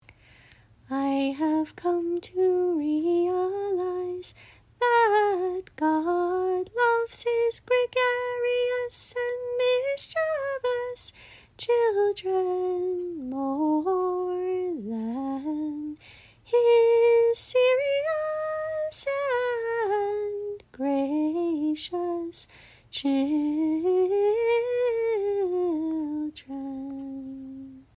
See individual song practice recordings below each score.
Many, especially the ones below the individual scores, were recorded on an old, portable cassette tape and have some distortion.